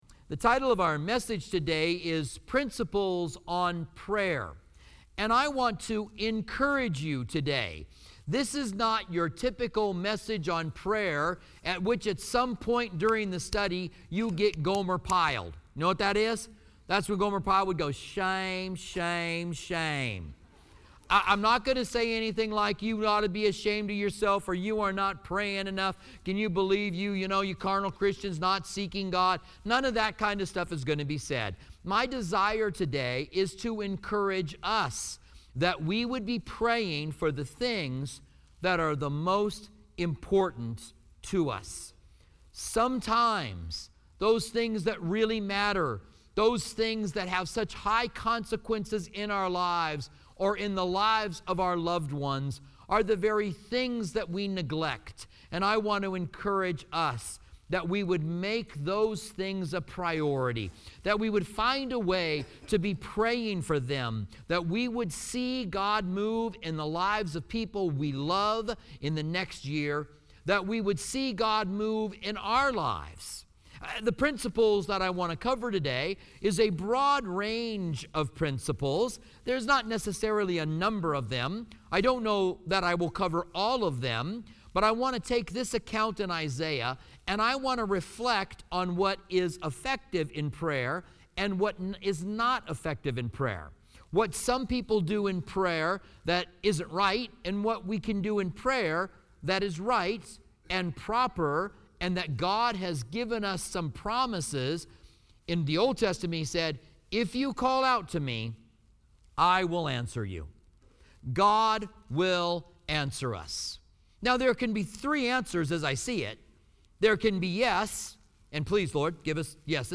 Topics Prayer